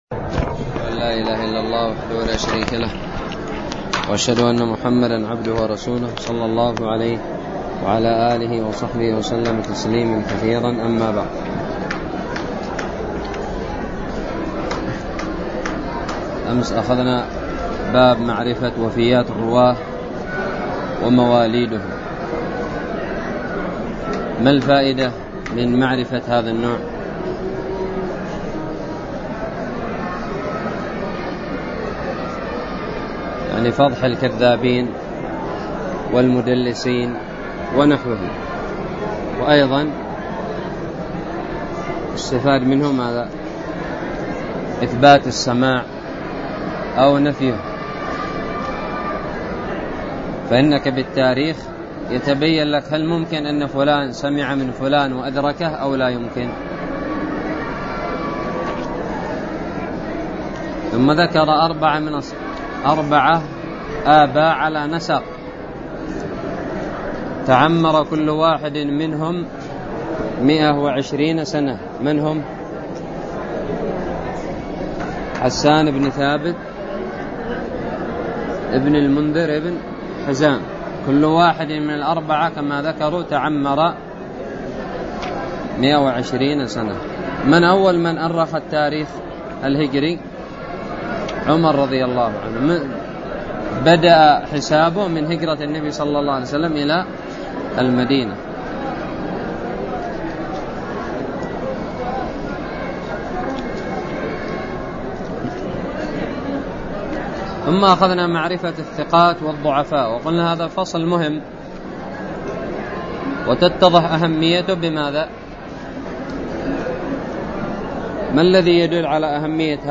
الدرس السادس والخمسون والأخير من شرح كتاب الباعث الحثيث
ألقيت بدار الحديث السلفية للعلوم الشرعية بالضالع